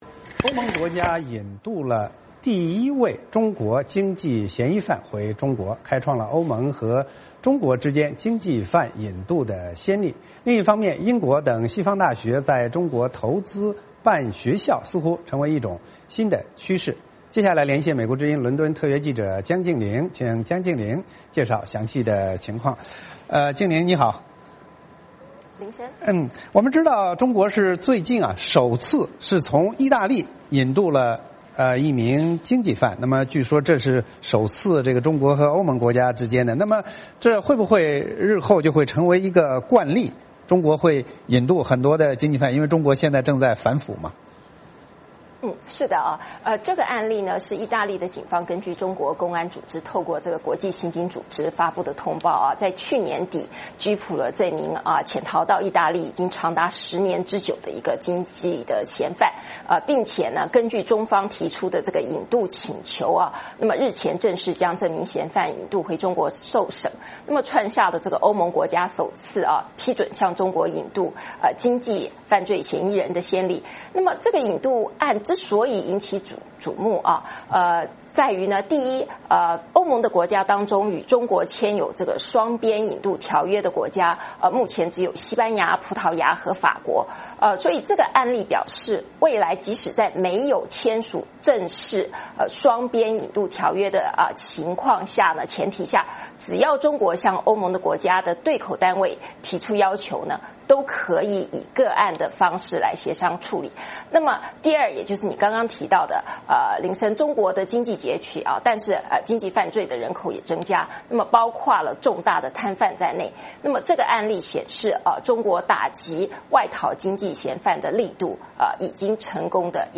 VOA连线：中国首次从意大利引渡经济犯